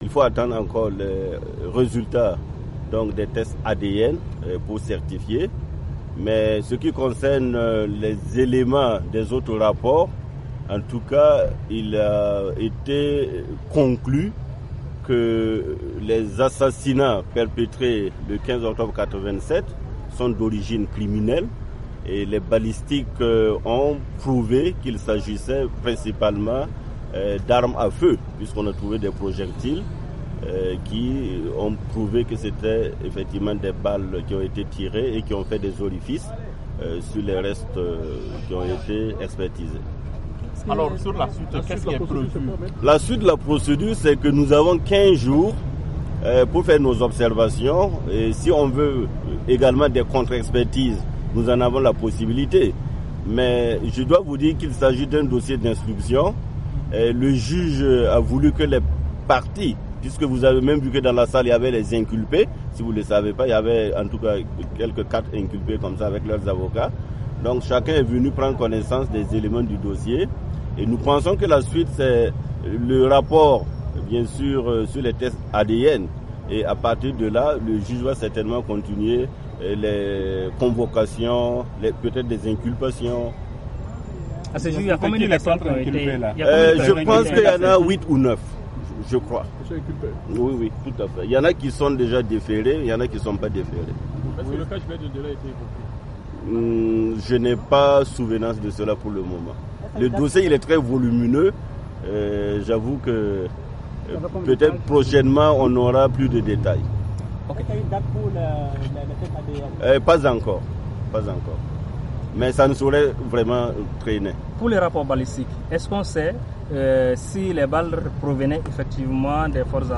Me Bénéwendé Stanislas Sankara lors d'une point de presse à Ouagadougou, Burkina